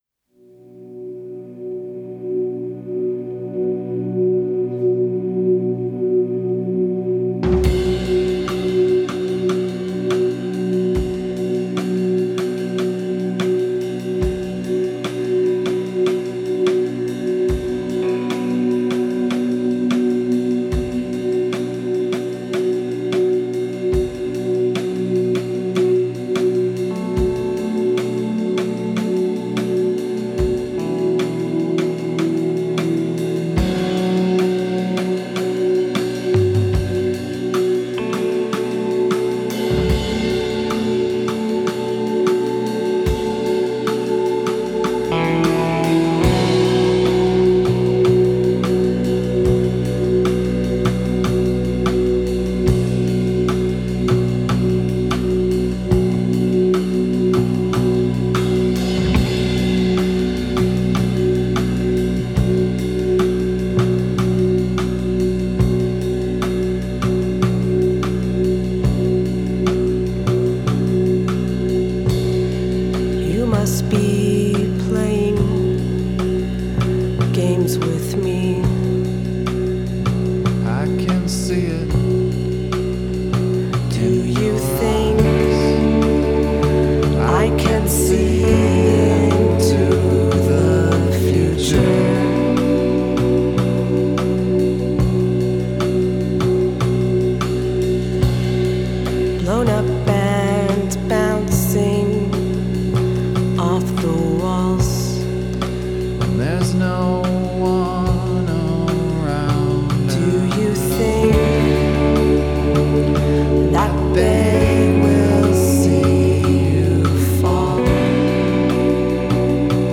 indie rock